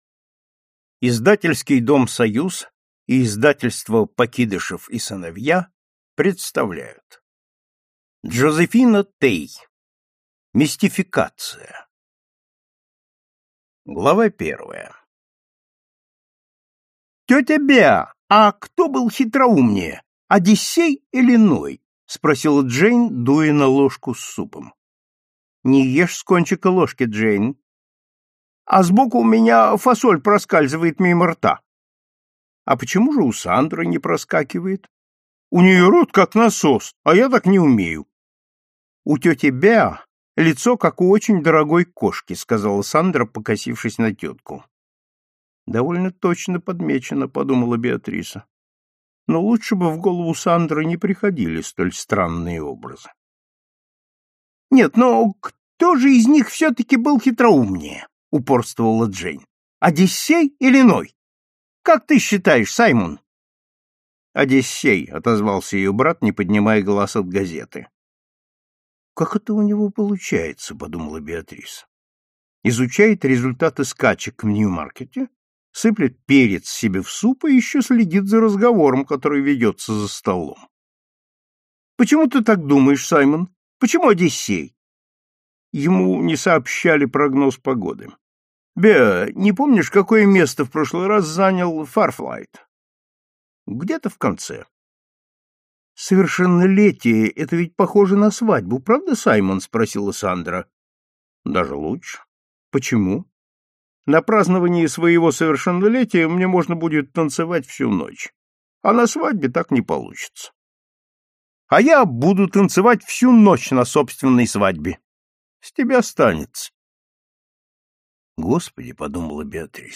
Аудиокнига Мистификация | Библиотека аудиокниг